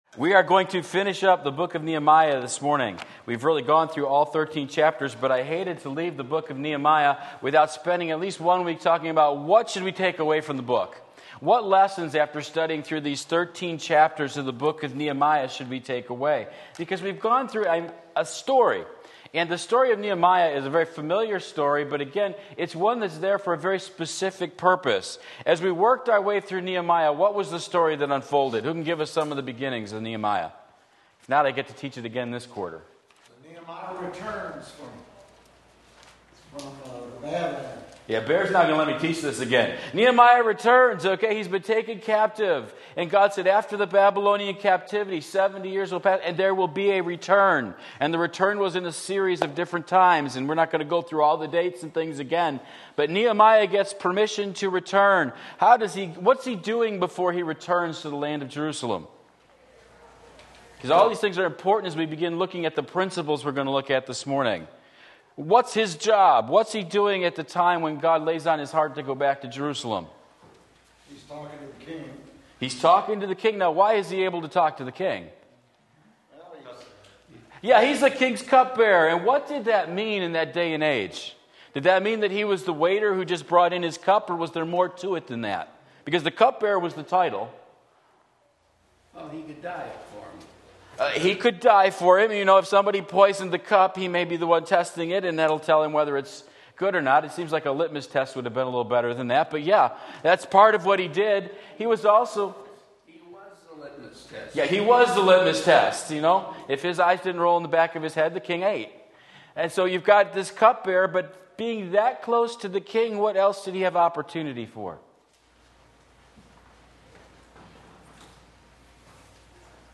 Sermon Link
Sunday School